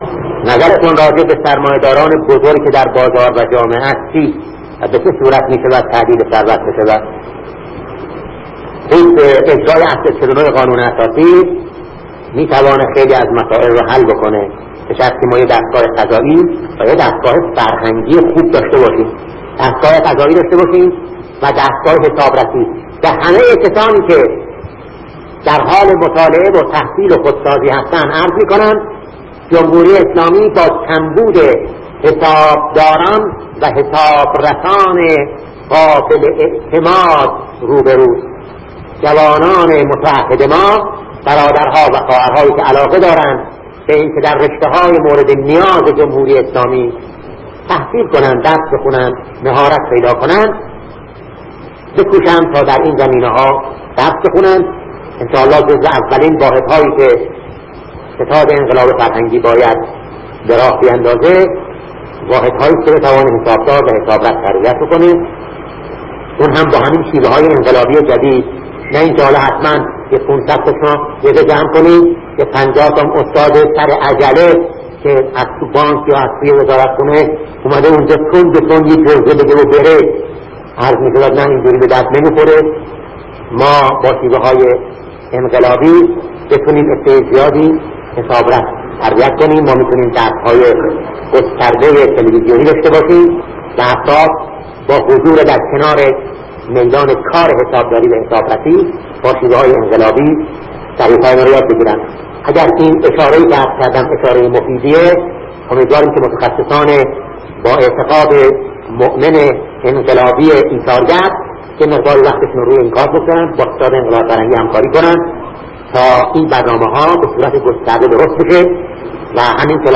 مطالبی ارزشمند از زبان شهید دکتر بهشتی در خصوص حرفه ی حسابداری و حسابرسی در